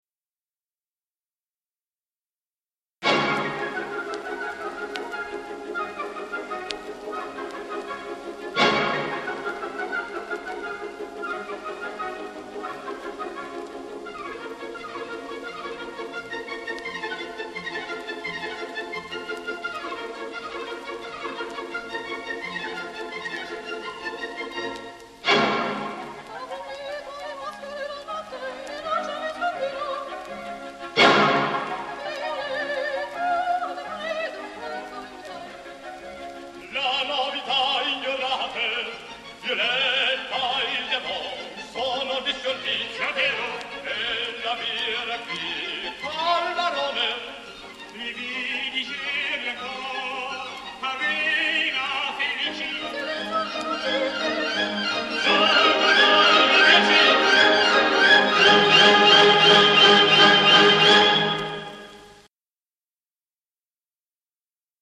(Flora-il Marchese-il Dottore)
mezzo soprano